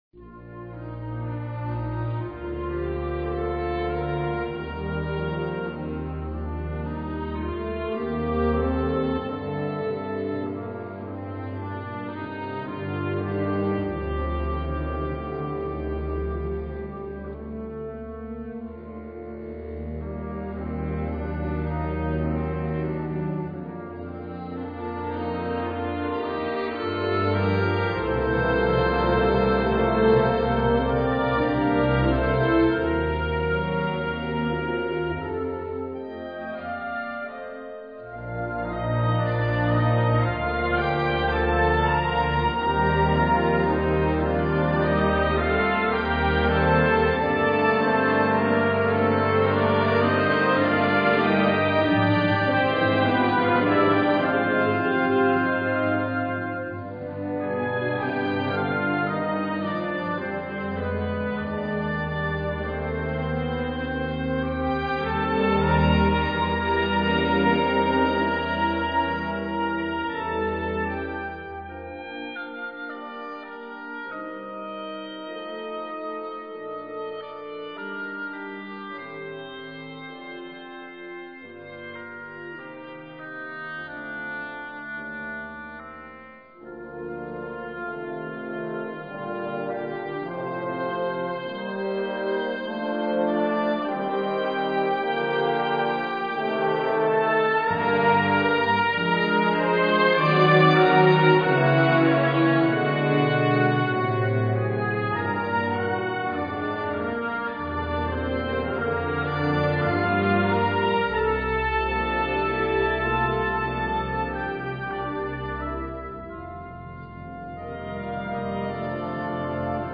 Kategorie Blasorchester/HaFaBra
Unterkategorie Ouvertüre (Originalkomposition)
Besetzung Ha (Blasorchester)